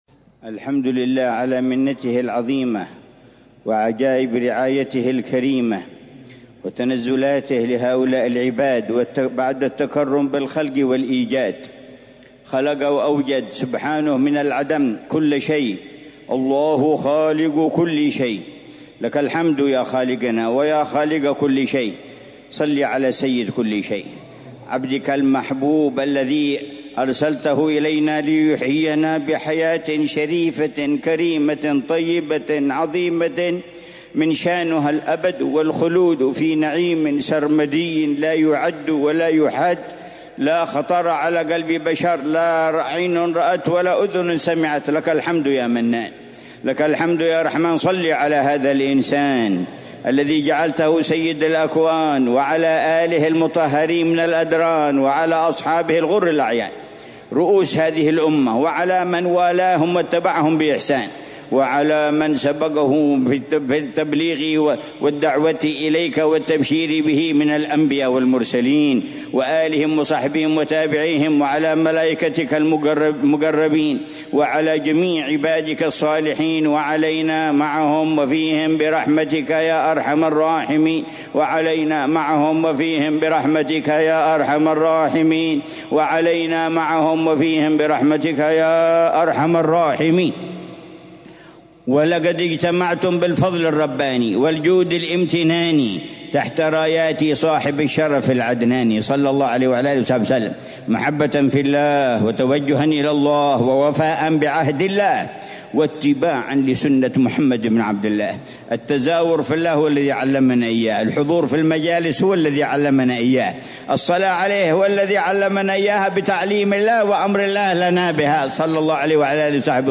محاضرة العلامة الحبيب عمر بن محمد بن حفيظ في المولد السنوي بدار المصطفى، ظهر الإثنين 23 ربيع الأول 1447هـ بعنوان: